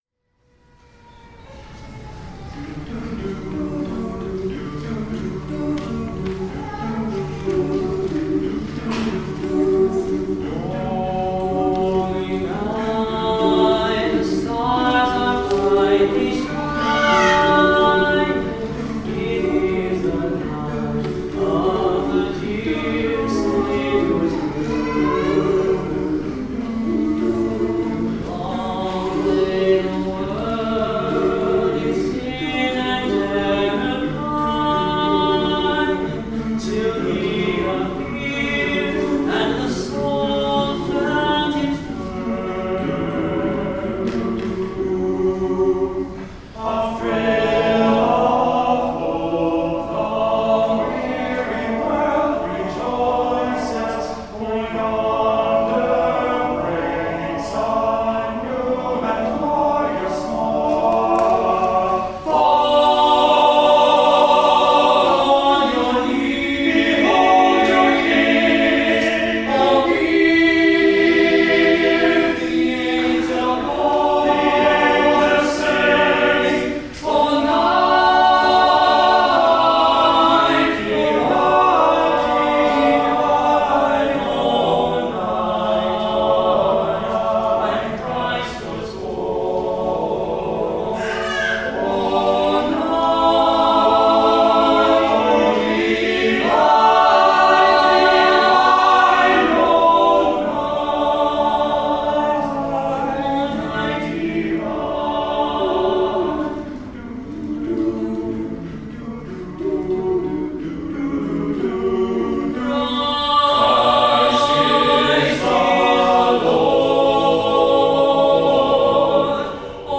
Performance of the TTBB version